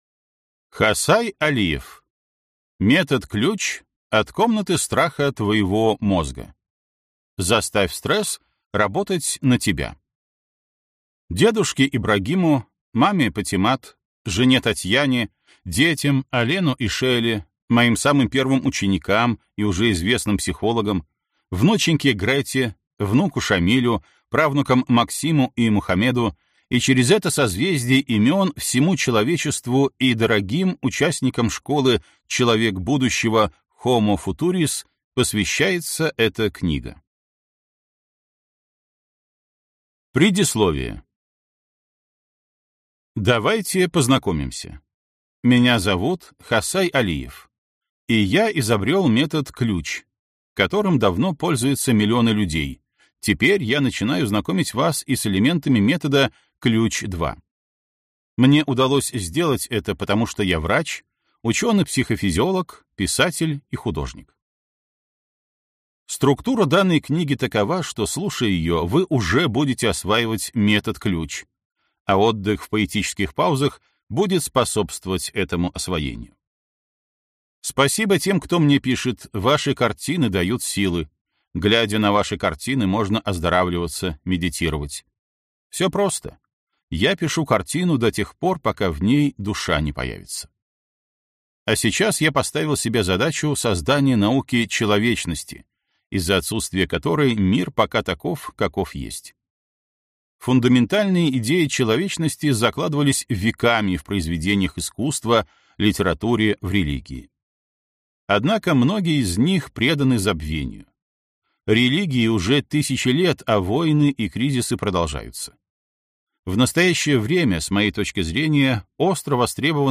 Аудиокнига Метод «Ключ» от комнаты страха твоего мозга. Заставь стресс работать на тебя | Библиотека аудиокниг